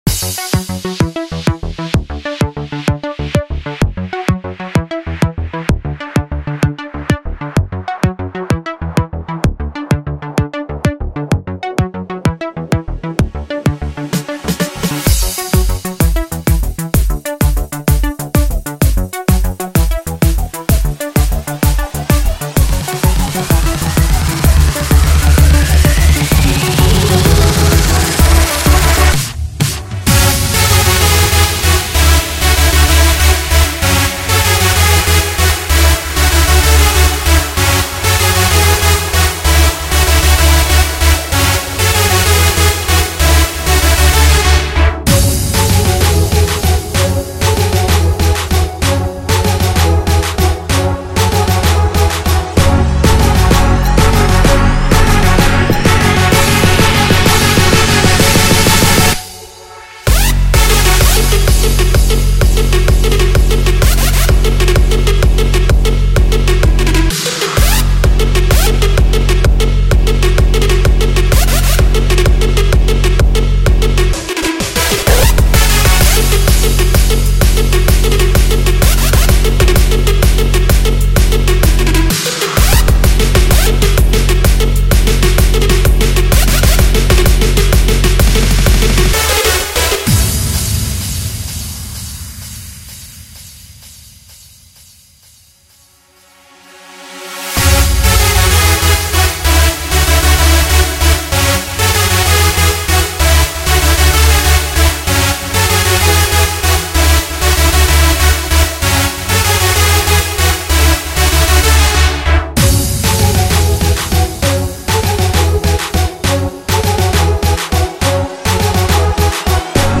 You may call it a remix if you wish.